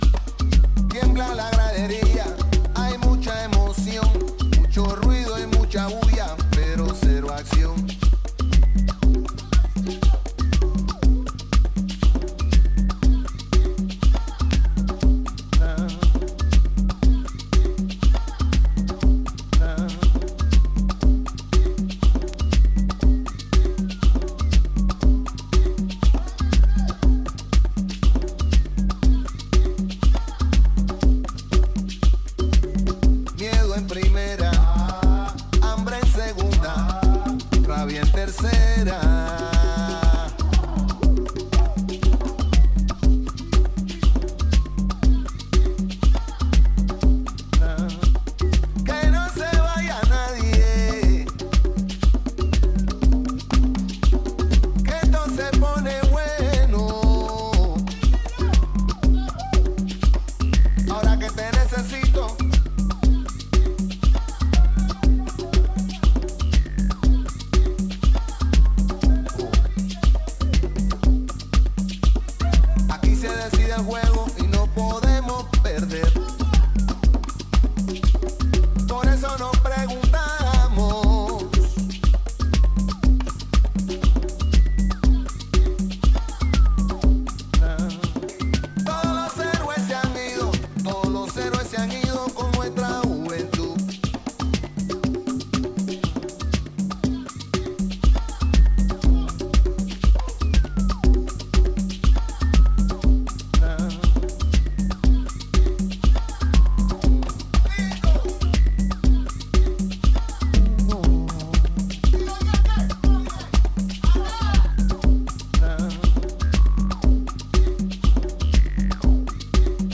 アフロチャントと軽快なボンゴ、さりげなく曲がったシンセが上げも下げもせず11分に渡って旋回するミニマル・ハウスのA面